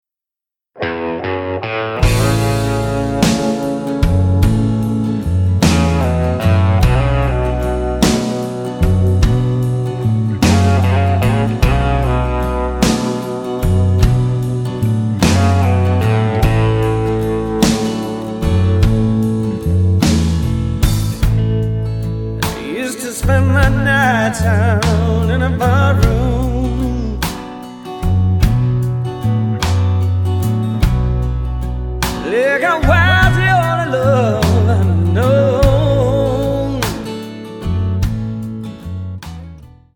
--> MP3 Demo abspielen...
Tonart:A Multifile (kein Sofortdownload.
Die besten Playbacks Instrumentals und Karaoke Versionen .